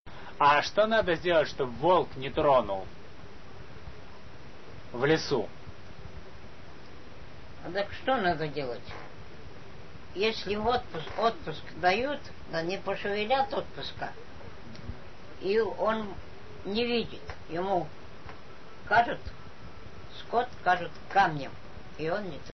Рассказ